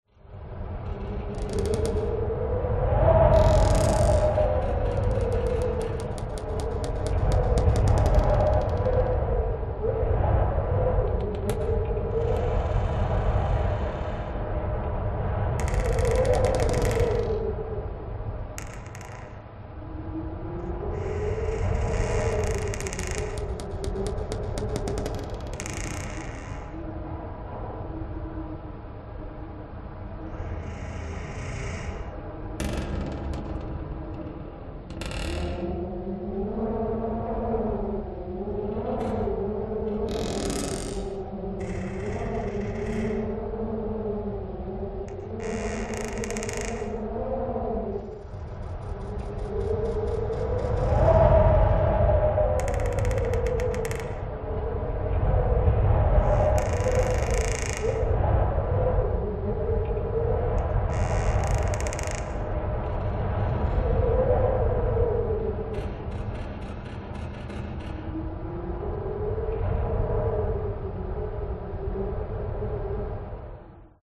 Здесь вы найдете жуткие скрипы полов, завывание ветра в broken окнах, шепот пустых комнат и другие эффекты.
Звук мощного ветра в покинутом древнем деревянном доме